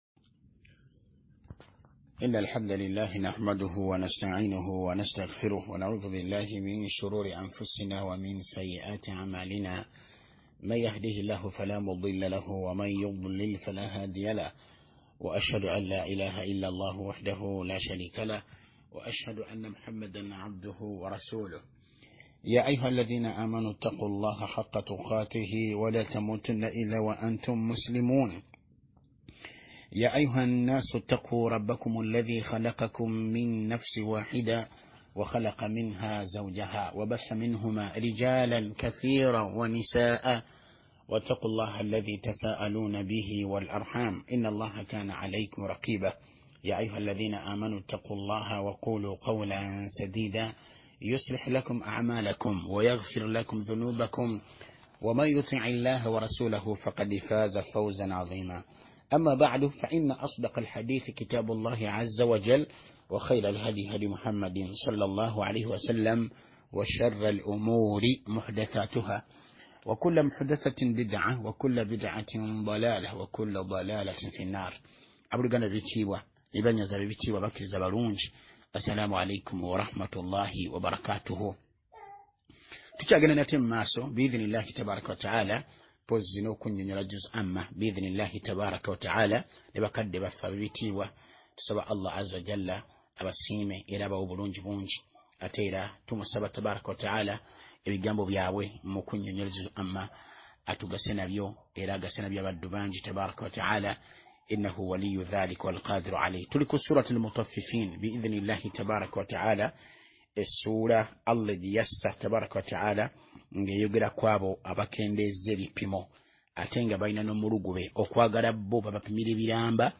Wuliliza Emisomo gya ba ma sheikh be uganda